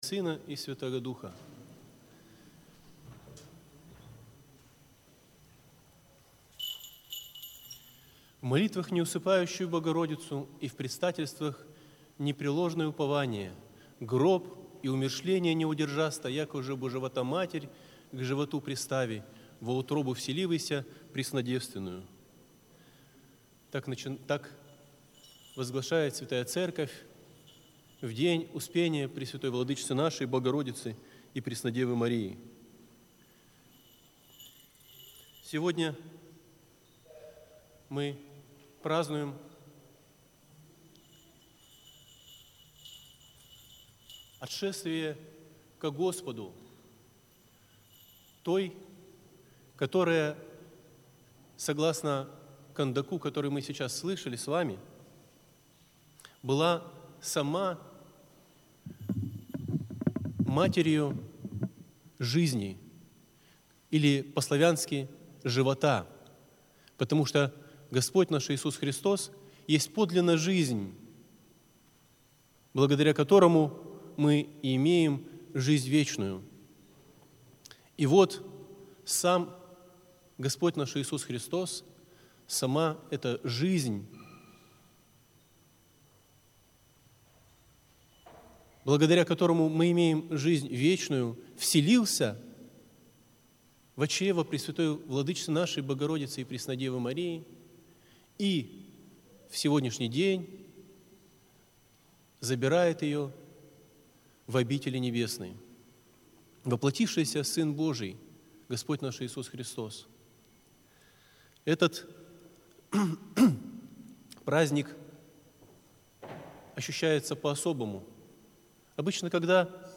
Аудиозапись проповеди